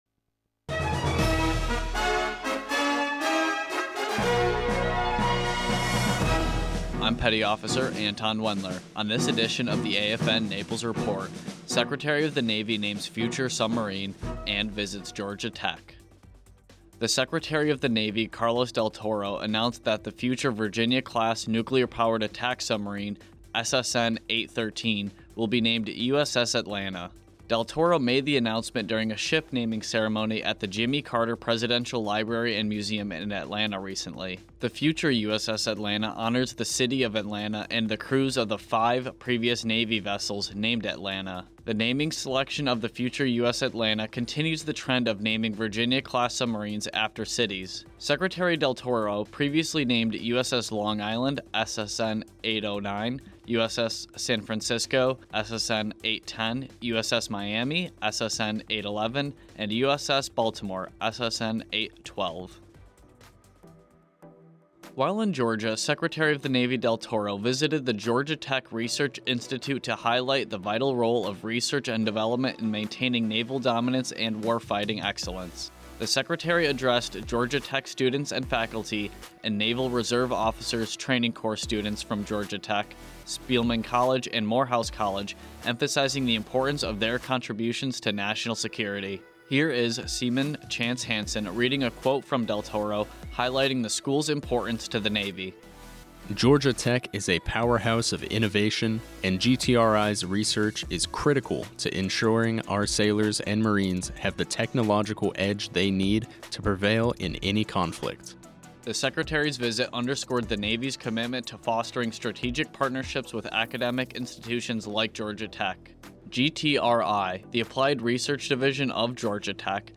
AFN Naples Radio News Carlos Del Toro
NewsAFNRadioNaples